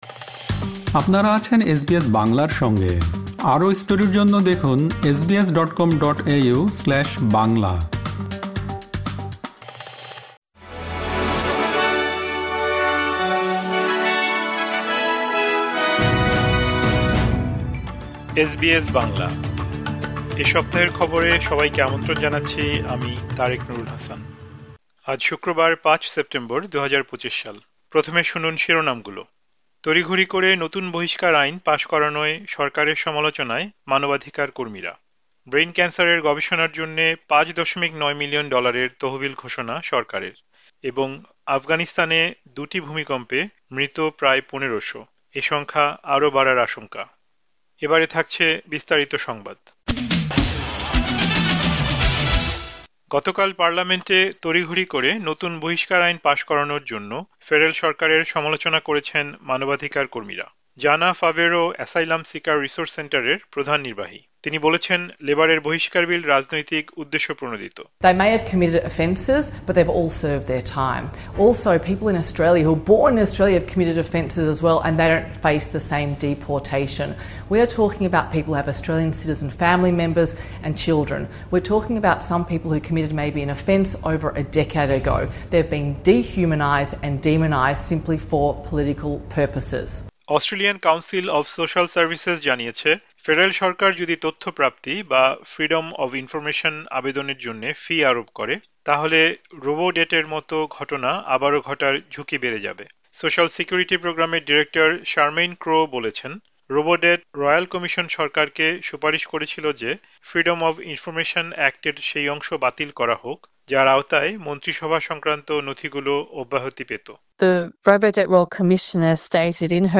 অস্ট্রেলিয়ার এ সপ্তাহের জাতীয় ও আন্তর্জাতিক গুরুত্বপূর্ণ সংবাদ শুনতে উপরের অডিও-প্লেয়ারটিতে ক্লিক করুন।